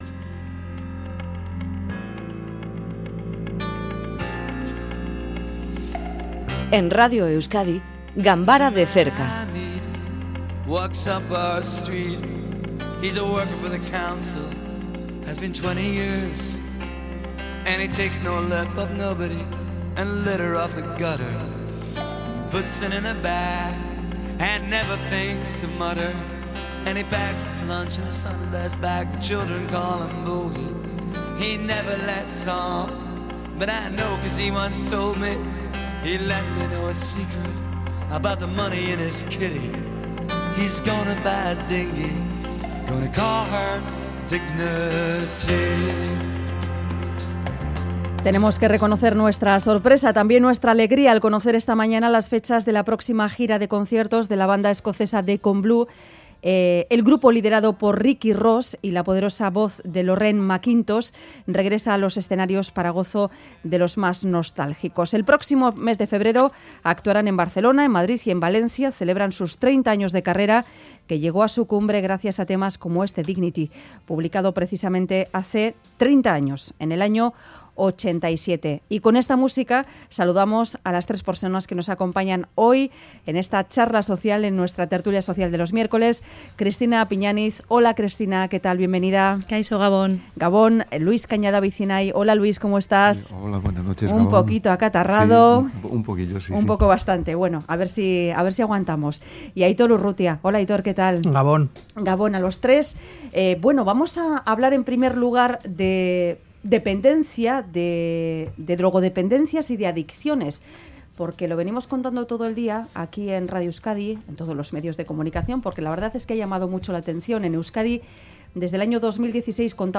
Tertulia Social: El séptimo continente, la isla de basura